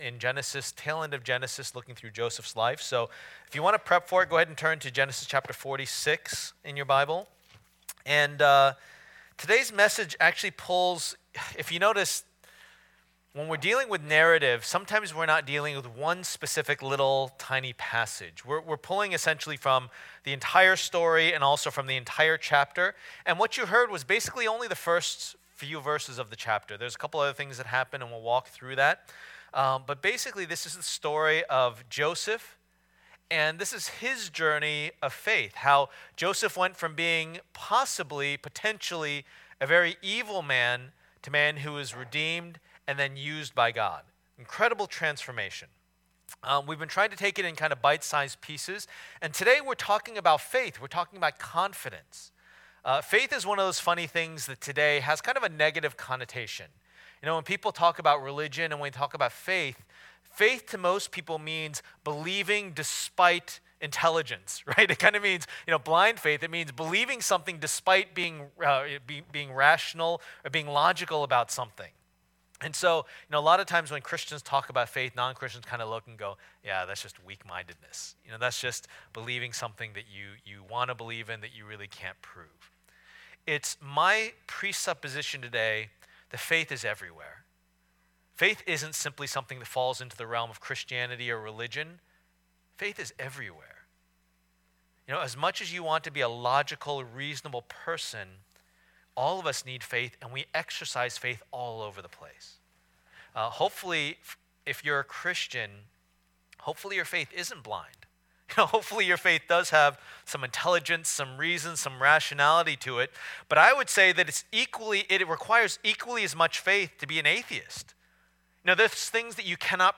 Preacher
Service Type: Lord's Day